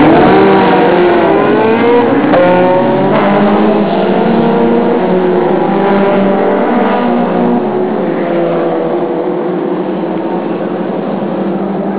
スターティング・グリッドに各車が整列しました。
スタートの音は